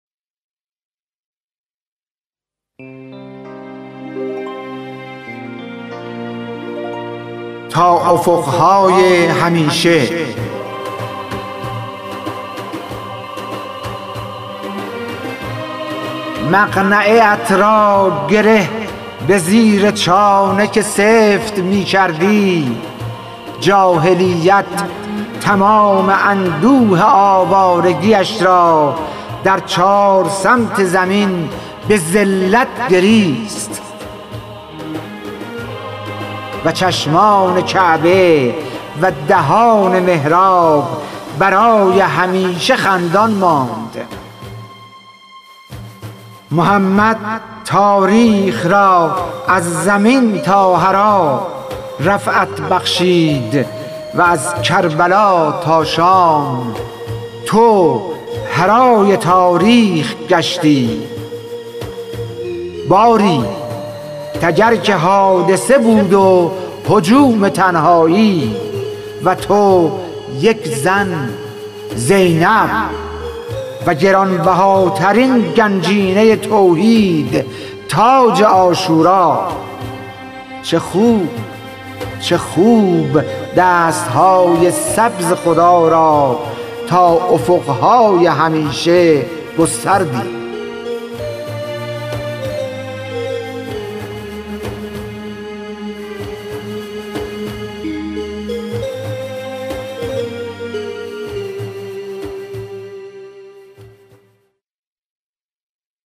خوانش شعر سپید عاشورایی / ۲